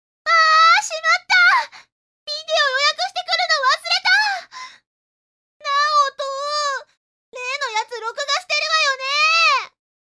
１７歳／女
■　Voice　■